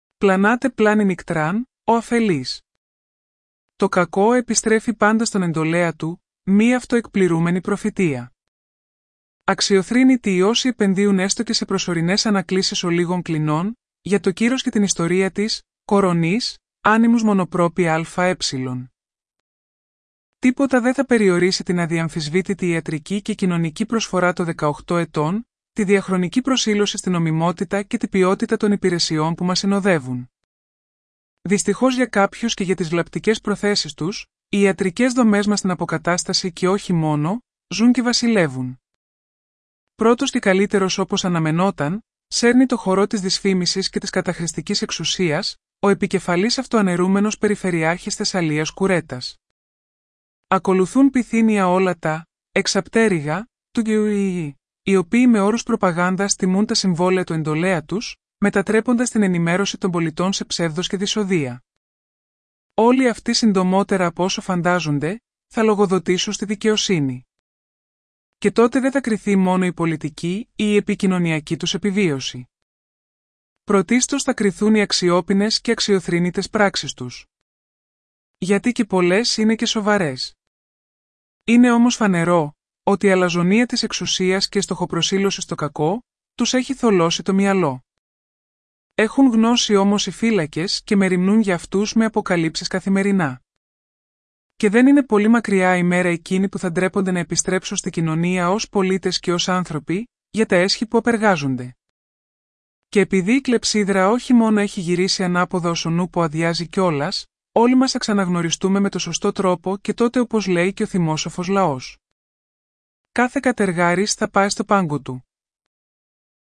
AI υποστηριζόμενο ηχητικό περιεχόμενο